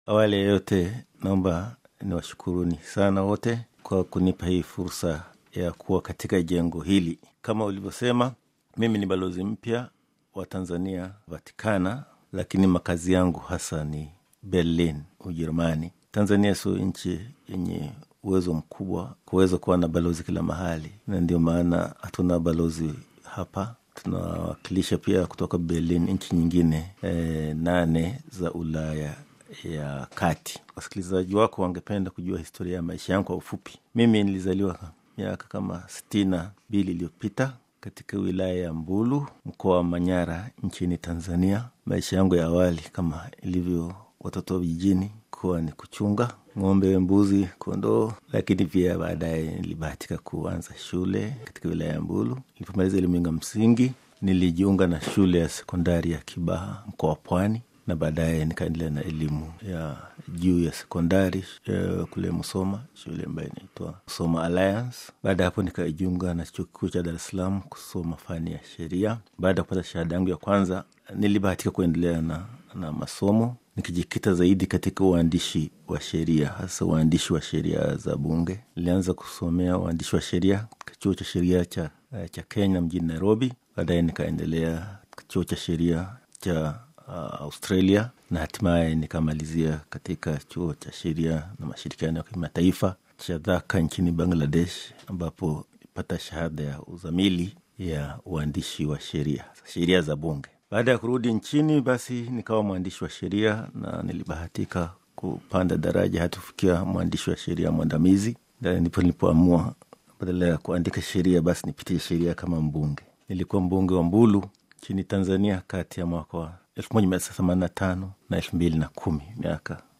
Mheshimiwa Philip Marmo, Balozi wa Tanzania mjini Vatican katika mahojiano maalum na Radio Vatican anaelezea kwa ufupi historia na uzoefu wake katika taaluma ya kutunga sheria za Bunge, Miaka 25 ya Ubunge pamoja na changamoto alizokabiliana nazo katika uwanja wa kisiasa nchini Tanzania.